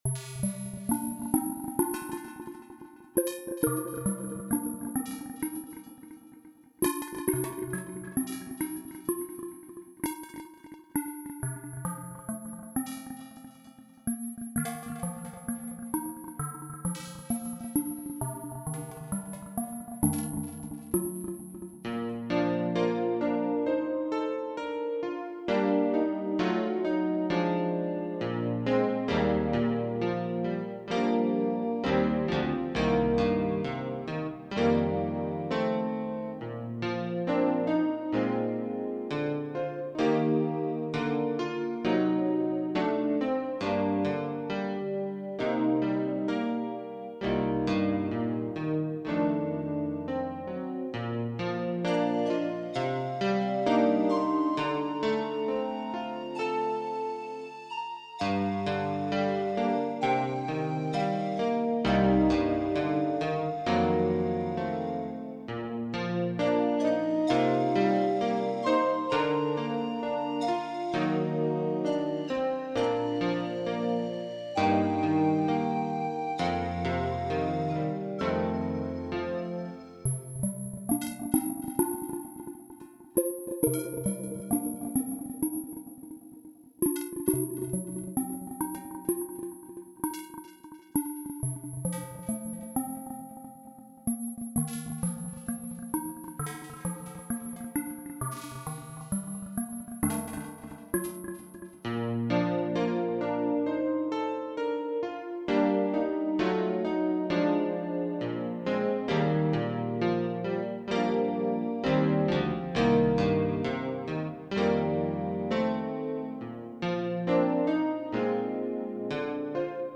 BGM
スローテンポロング穏やか